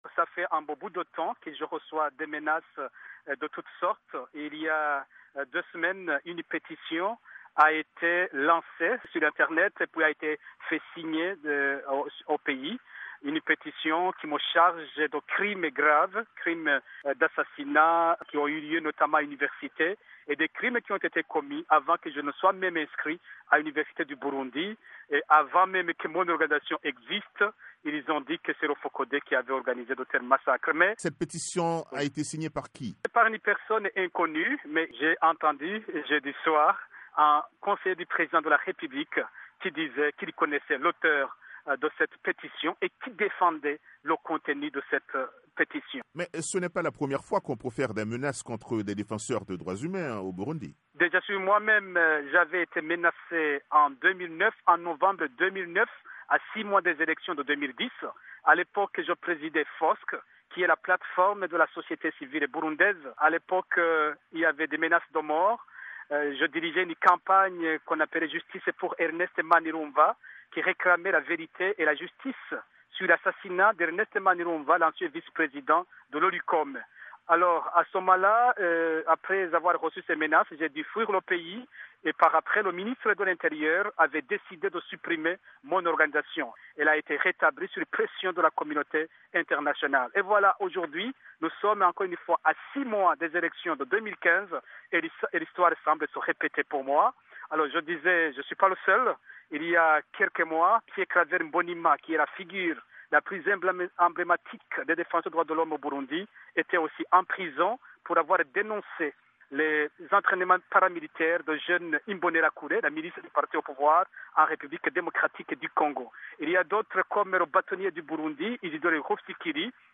a joint par téléphone